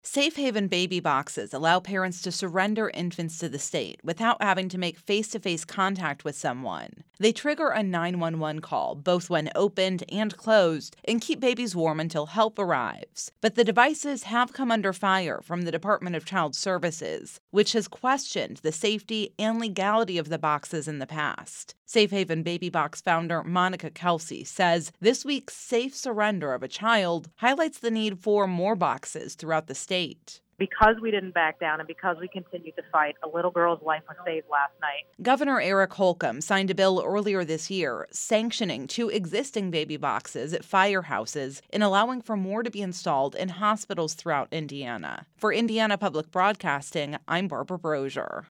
Indiana Public Radio, a listener-supported service of Ball State University